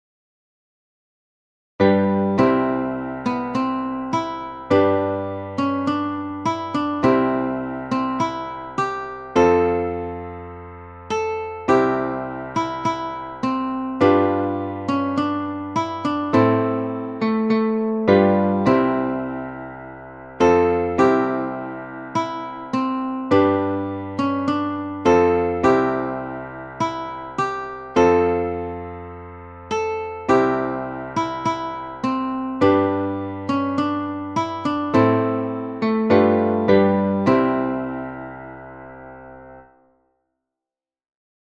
Auld Lang Syne in C audio file from musescore (same as above)